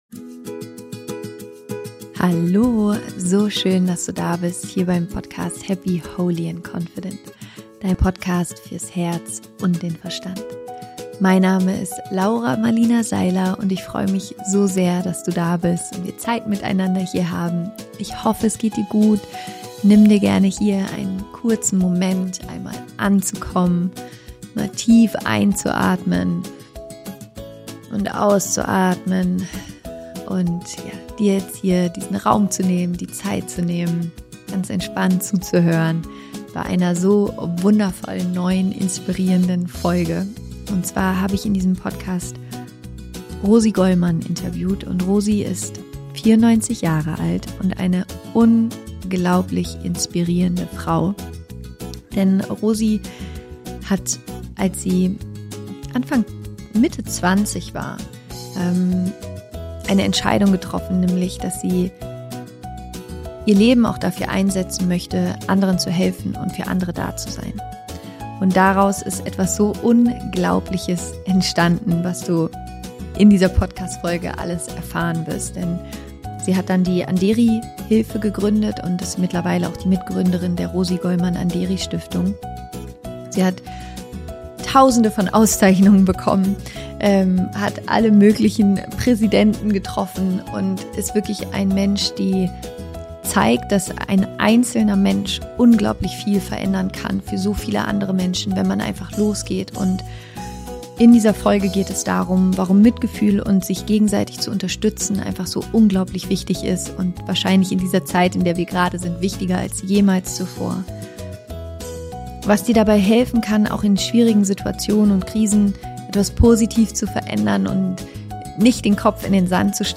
Wie ein Mensch die Welt verändern kann - Interview Special